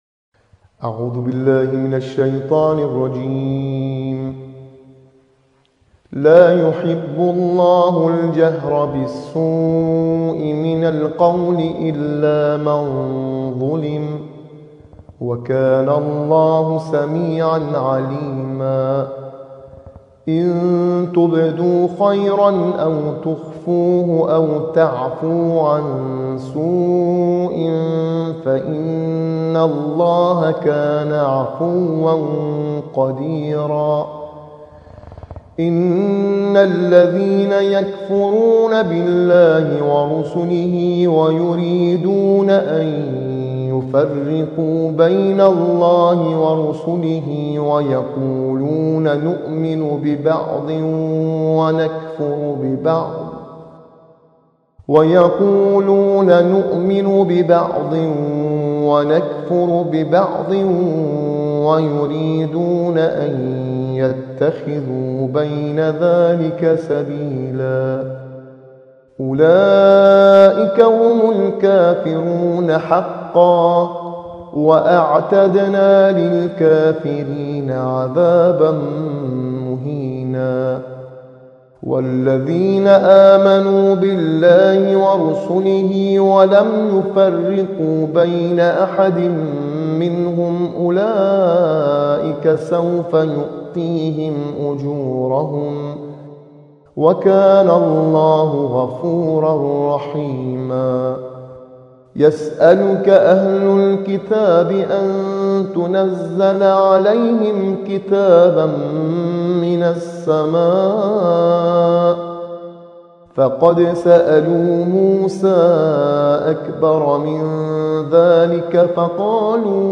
آموزش نغمات